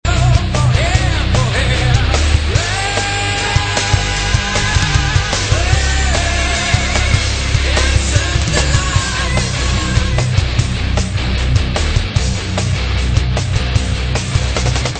stoner